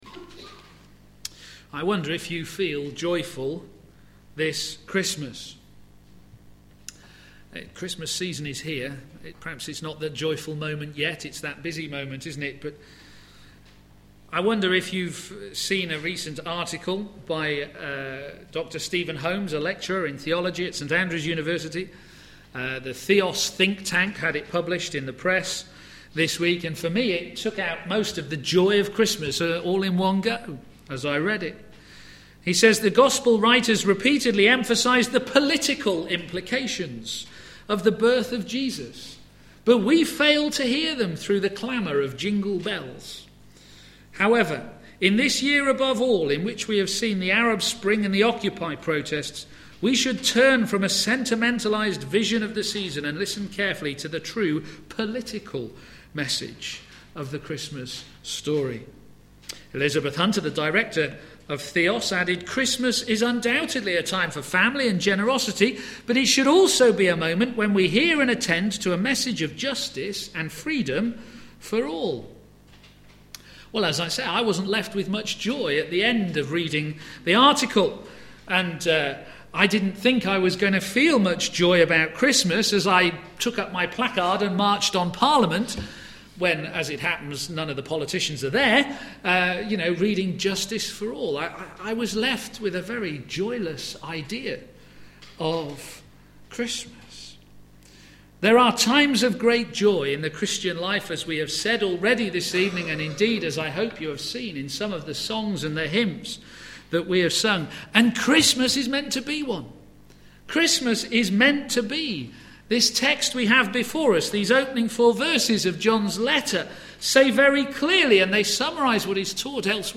Theme: Immanuel: God with us for joy Sermon